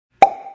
爆炸声
描述：通过合成声音创建
标签： 爆炸 巨响 pop
声道立体声